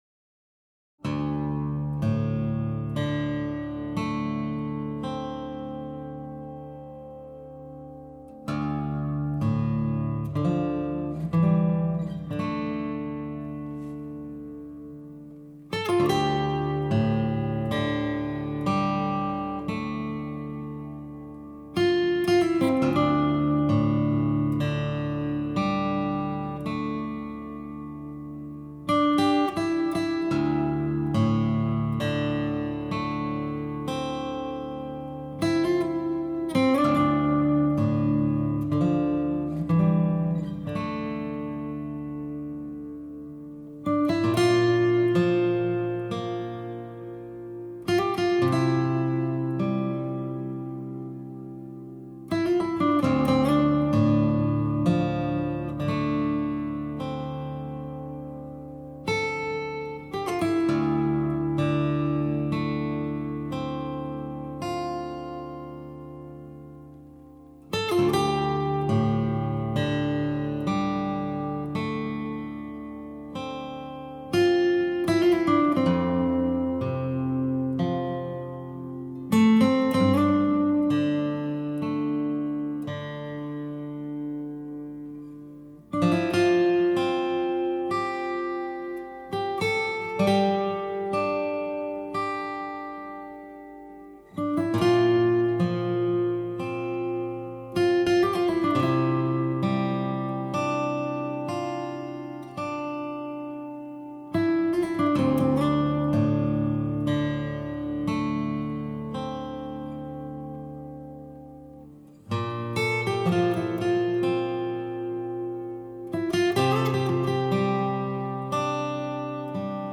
only guitars